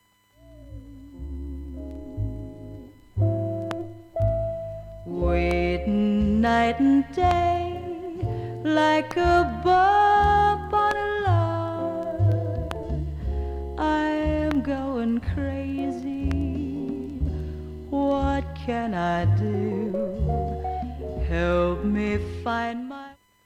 クリアな音質で音質良好です、
A-4中盤に9回のかすかなプツが出ます。
ちなんだスタンダードヴォーカル集。
ストリングスとギターで味付けした情感溢れる曲を。